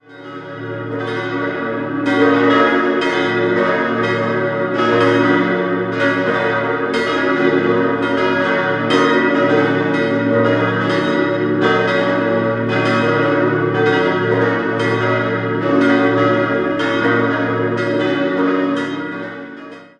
Im Lauf der Geschichte gab es mehrere Gotteshäuser im Ort, das heutige stammt aus den Jahren 1761/63 und ist reich ausgestattet. 5-stimmiges Geläute: b°-des'-es'-ges'-b' Alle Glocken wurden 1911 von der Gießerei Rüetschi in Aarau gegossen.